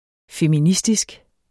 Udtale [ femiˈnisdisg ]